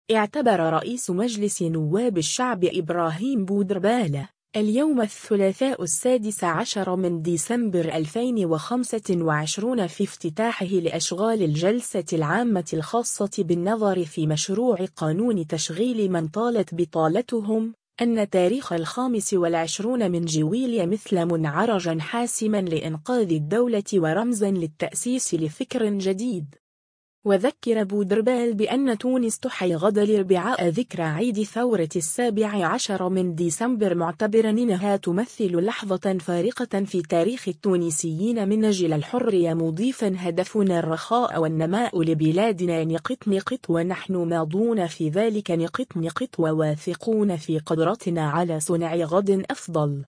اعتبر رئيس مجلس نواب الشعب ابراهيم بودربالة، اليوم الثلاثاء 16 ديسمبر 2025 في افتتاحه لأشغال الجلسة العامة الخاصة بالنظر في مشروع قانون تشغيل من طالت بطالتهم، أن تاريخ 25 جويلية مثل مُنعرجا حاسما لإنقاذ الدولة ورمزا للتأسيس لفكر جديد.